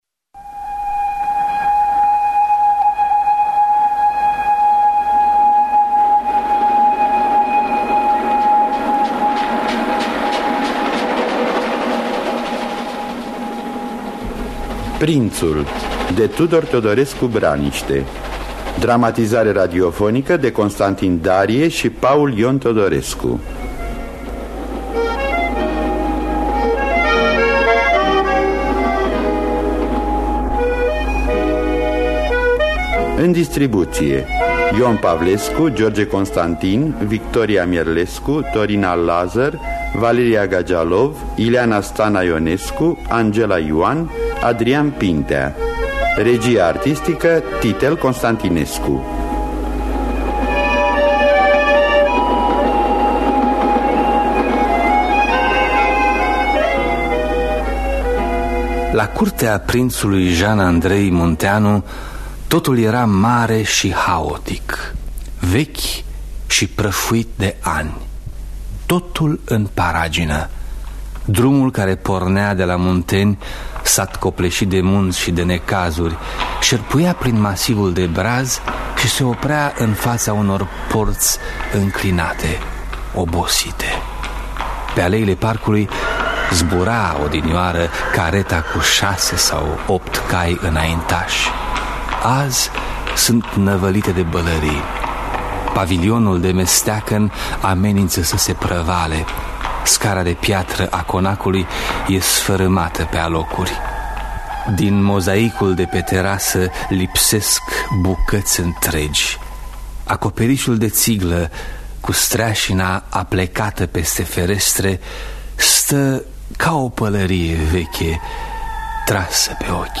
Dramatizare radiofonică